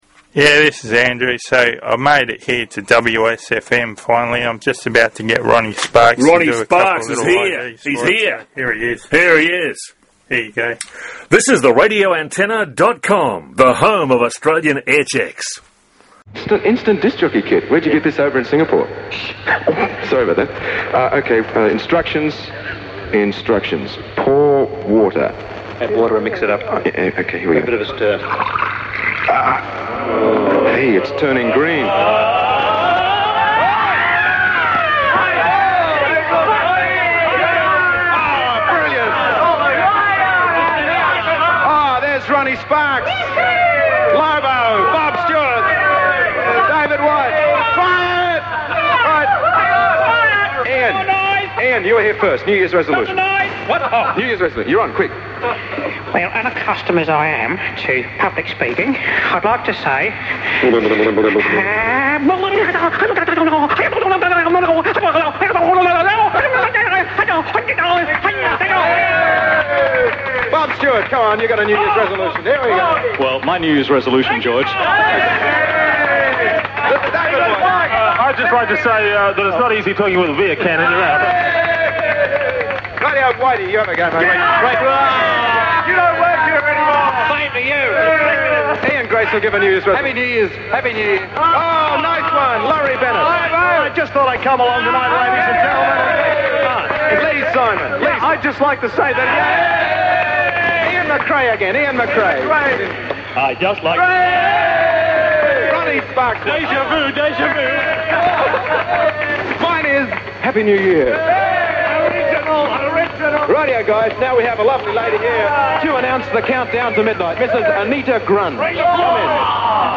RA Aircheck -2SM 74-75 NYE various jocks
RA-Aircheck-2SM-74-75-NYE-various-jocks.mp3